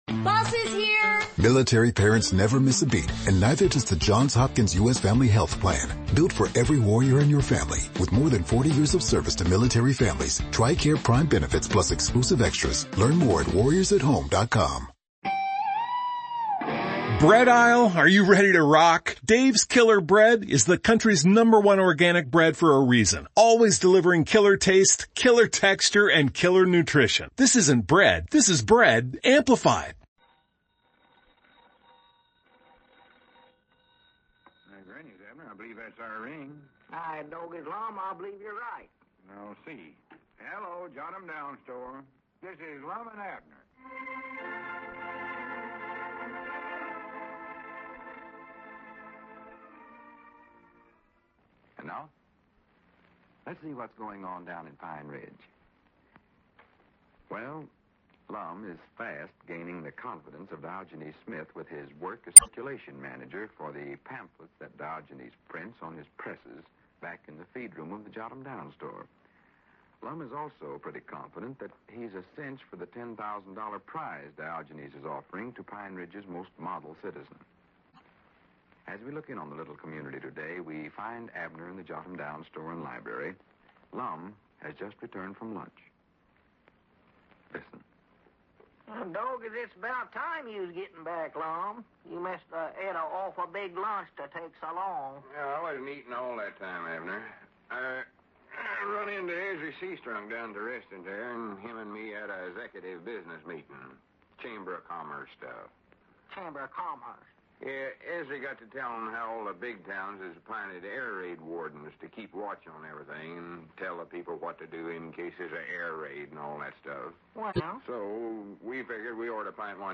A classic radio show that brought laughter to millions of Americans from 1931 to 1954.